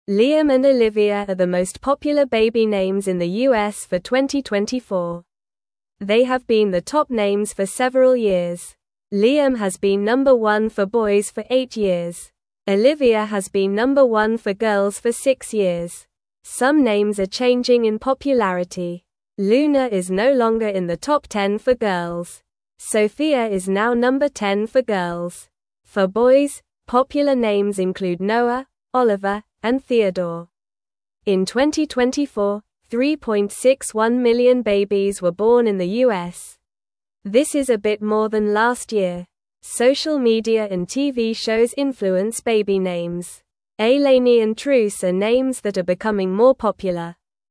Normal
English-Newsroom-Lower-Intermediate-NORMAL-Reading-Liam-and-Olivia-Are-Top-Baby-Names-for-2024.mp3